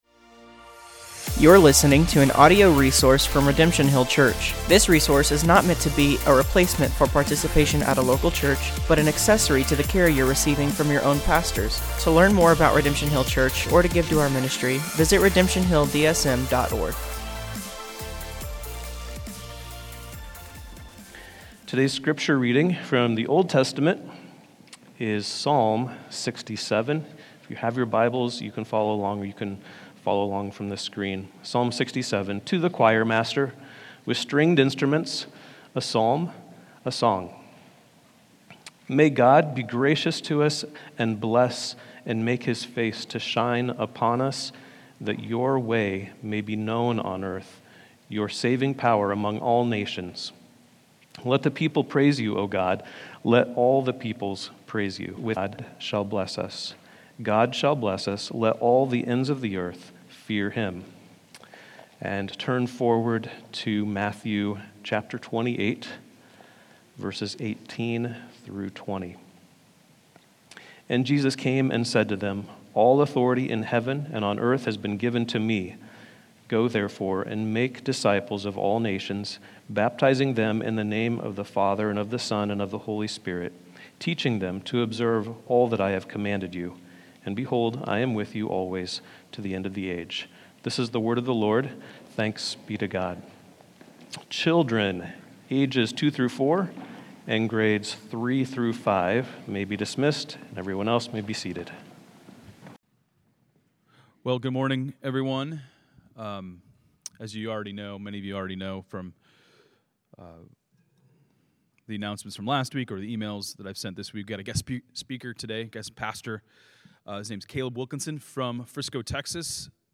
Sermons | Redemption Hill Church Des Moines, IA